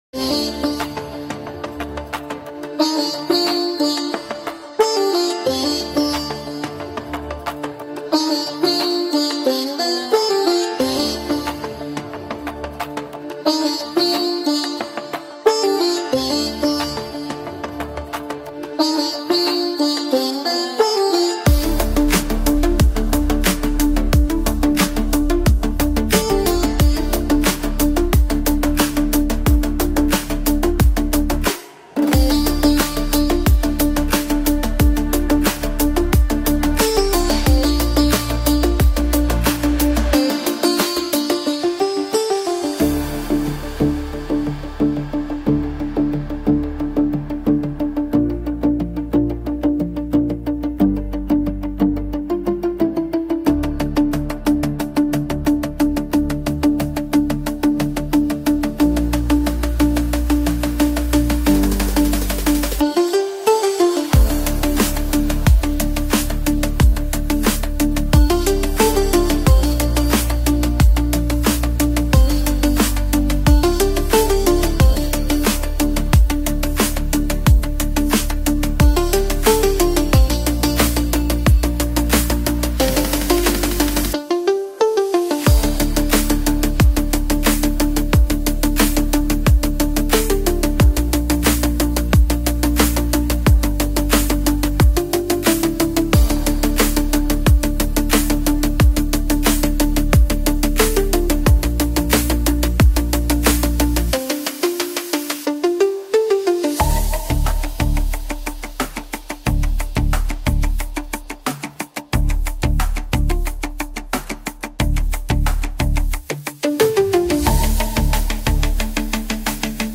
(Instrumental) 🎵 AI Generated Music